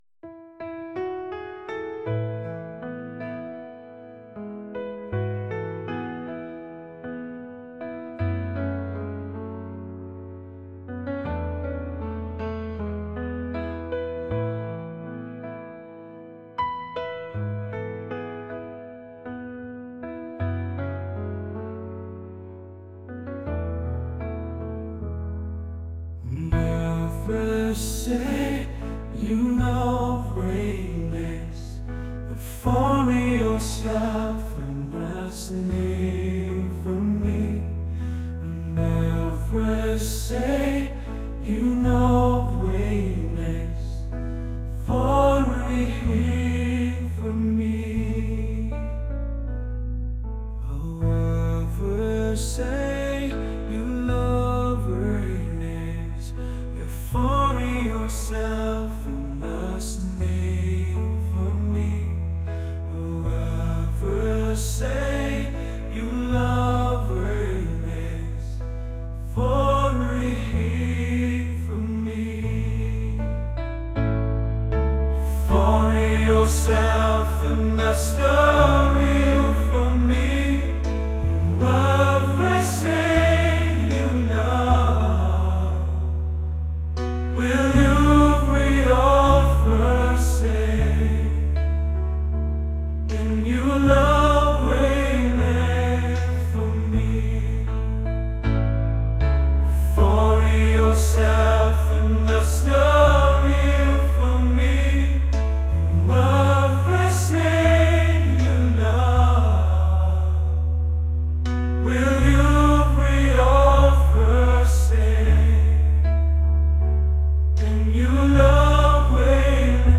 acoustic | pop | soul & rnb